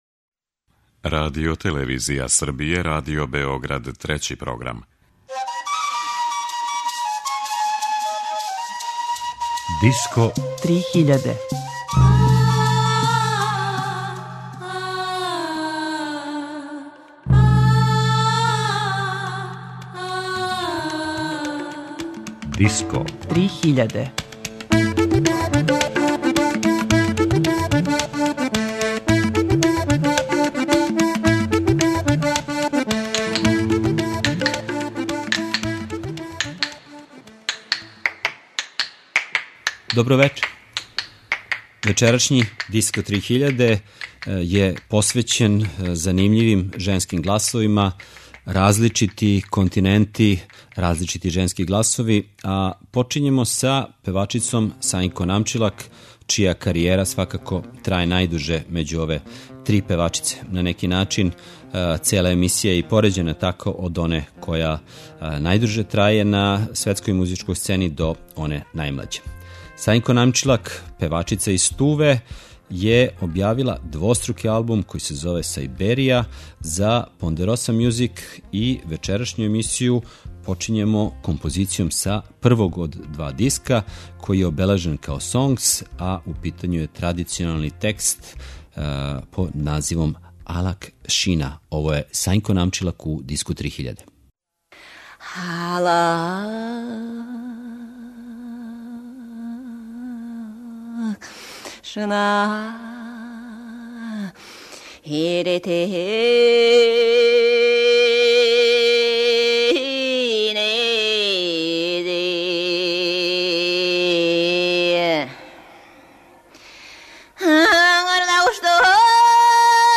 фадо музике